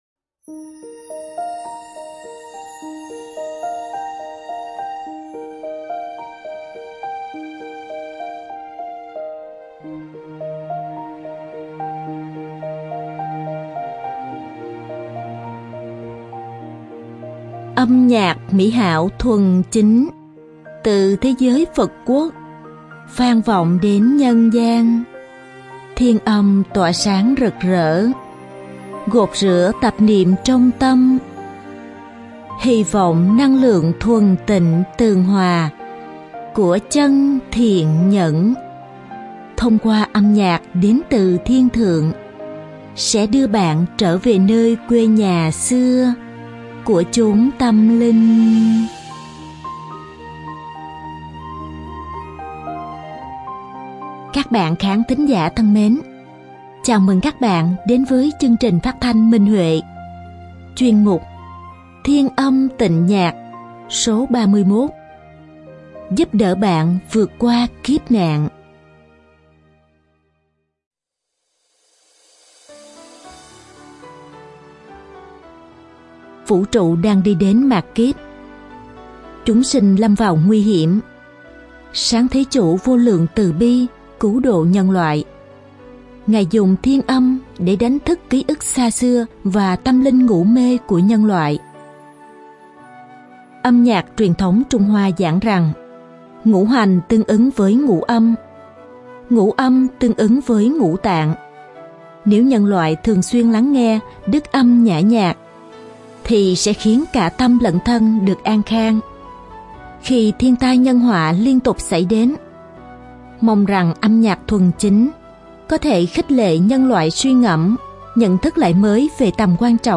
Độc tấu đàn tỳ bà